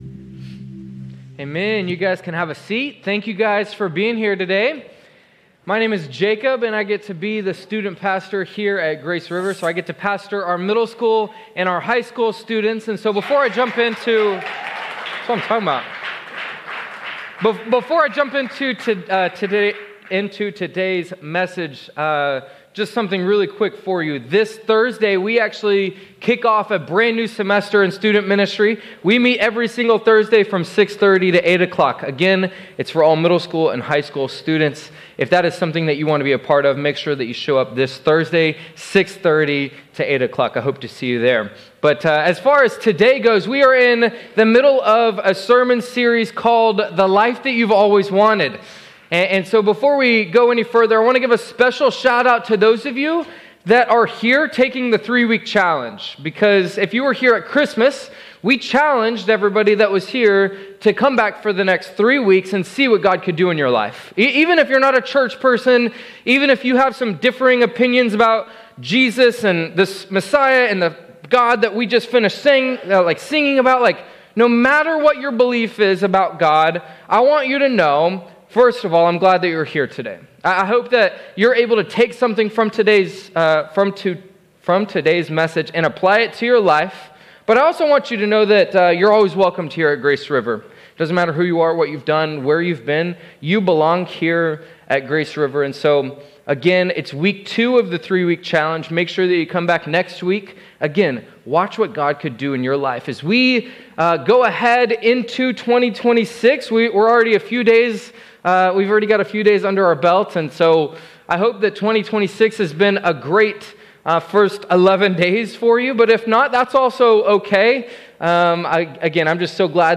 In this message from our The Life You’ve Always Wanted series, we explore why knowing God’s truth isn’t enough and how practicing margin and slowing down can lead to real peace. In a culture of hurry, Jesus invites us to live differently by accepting our limits, reordering our time, and creating space for relationship with Him.